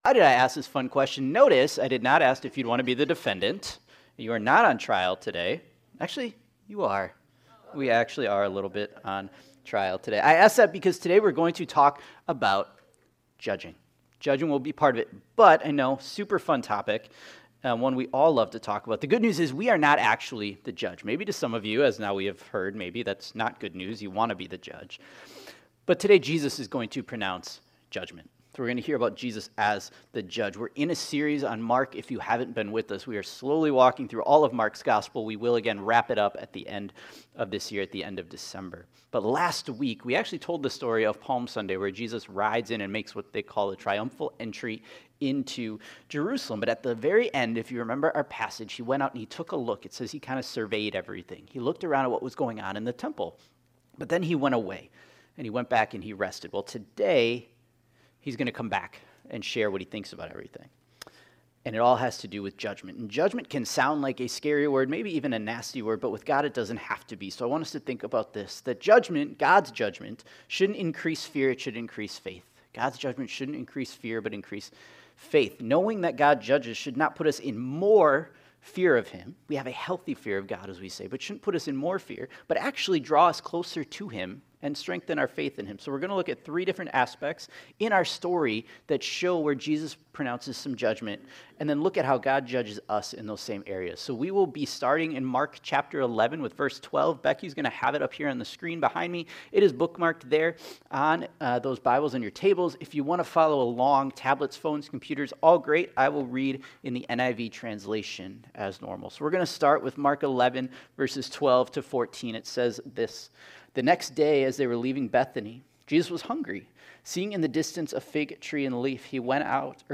Mark Faith Forgiveness Fruitfulness Judgment Prayer Purity Sunday Morning Judgement can sound like a nasty word sometimes.